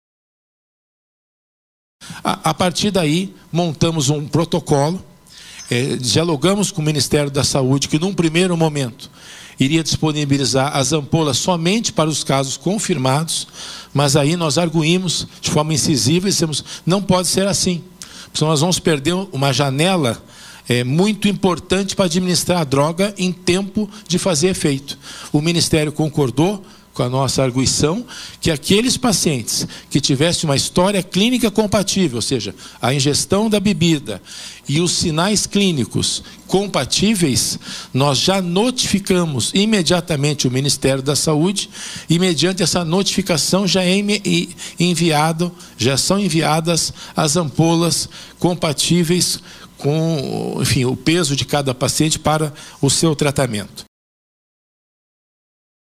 A Assembleia Legislativa do Paraná (ALEP) sediou, nesta quarta-feira (15), uma audiência pública para discutir os riscos do metanol à saúde e estratégias de combate à adulteração de bebidas alcoólicas.
O secretário de saúde em exercício, César Neves, esteve na audiência e falou sobre a aquisição de antídotos para pacientes que fossem internados após ingerir bebidas adulteradas.